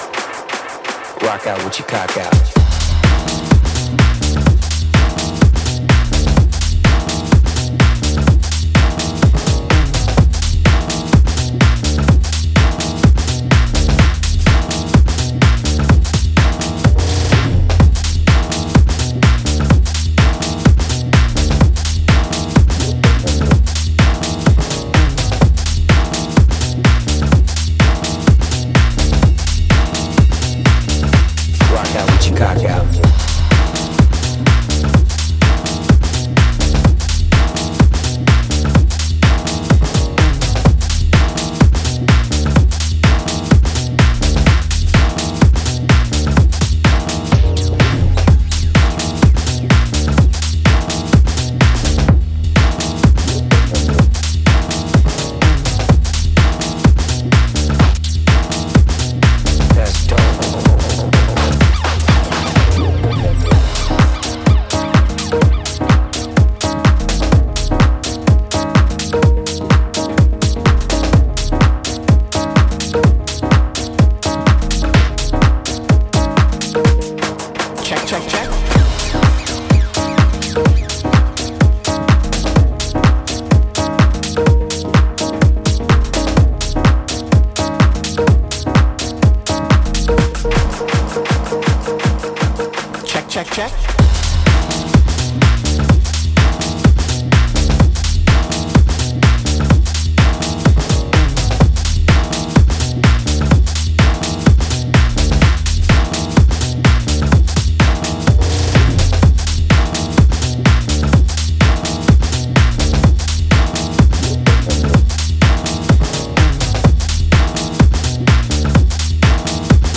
本作では、ヘヴィなボトムと分厚いベースラインで爆走する、ダークでパワフルなミニマル・テック・ハウスを展開。